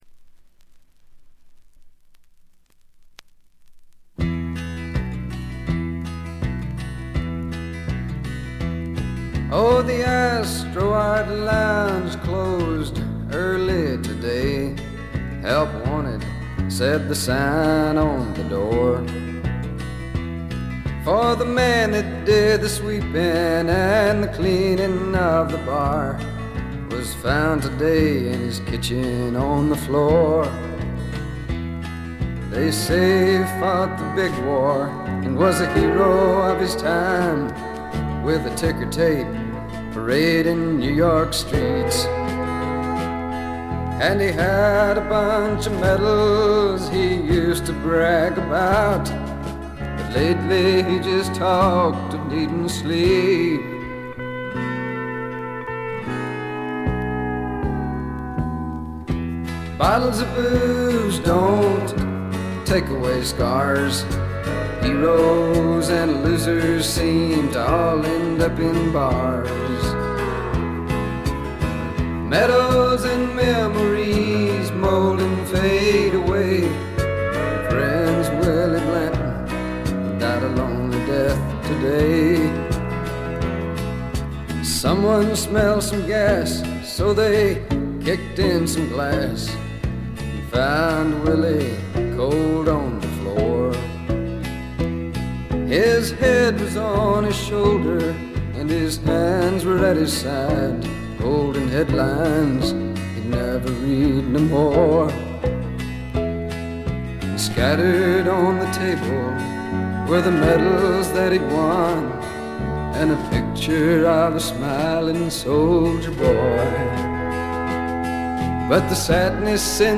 *** LP ： USA 1973
軽微なチリプチ。散発的なプツ音少し。
軽快なカントリー・ロックからスワンプ作、美しいバラードまで良曲がずらりと並びます。
ぶっきらぼうな歌い方は男の色気がプンプン。
試聴曲は現品からの取り込み音源です。